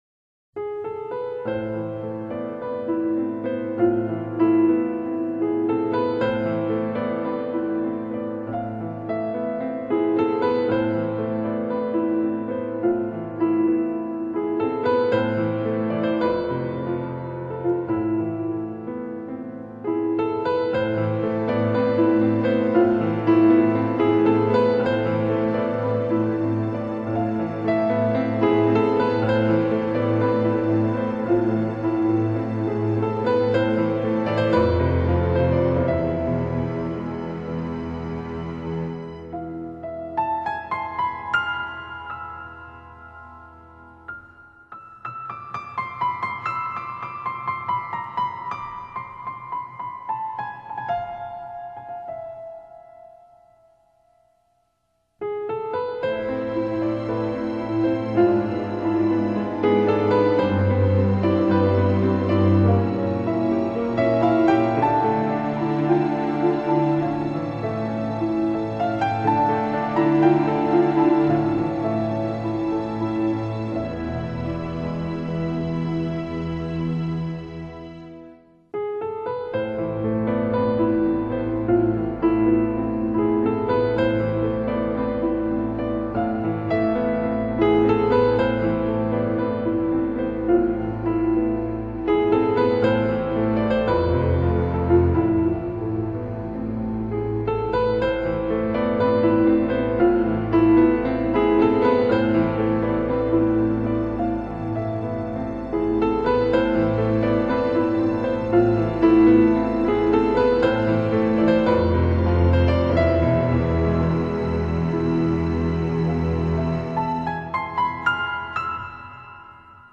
专辑制作精美，并利用催眠舞蹈、优雅的旋律、黑暗的材质、优秀的古典以及感性的歌唱等不同的风格特点，令人印象深刻。